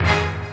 Banks Hit.wav